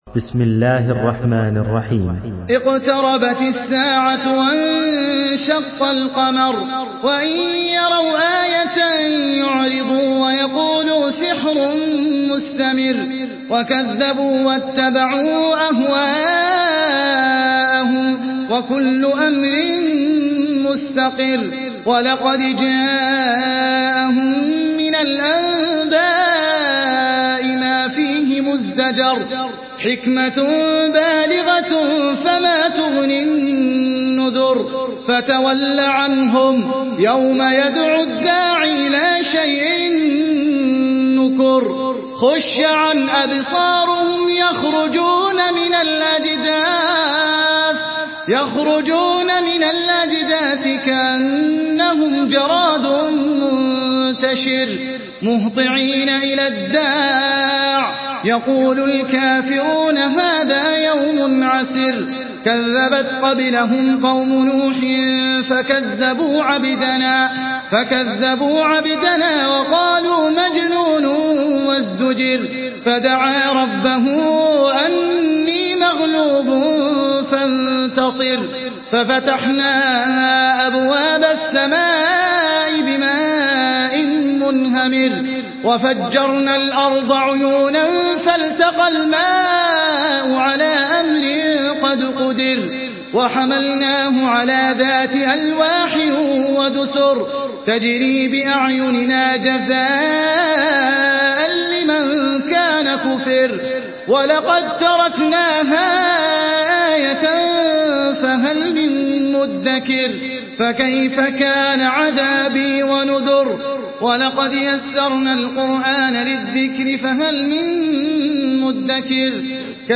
سورة القمر MP3 بصوت أحمد العجمي برواية حفص
مرتل حفص عن عاصم